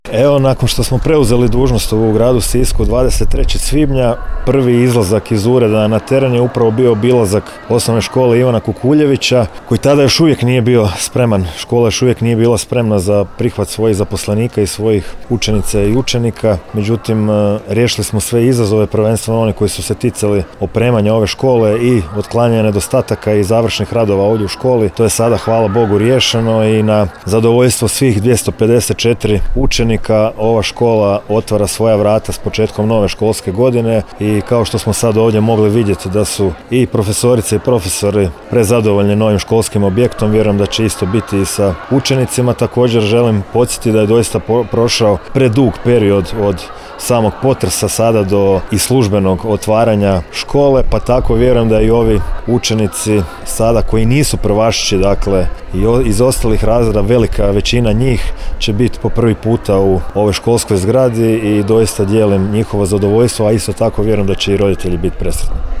Tim povodom gradonačelnik Siska Domagoj Orlić sa zamjenicom Sanjom Mioković obišao je školu u utorak, 26. kolovoza 2025. godine, te istaknuo da je upravo jedan od prioriteta nakon preuzimanja dužnosti gradonačelnika bio završetak radova te otklanjanje svih nedostataka koji su uočeni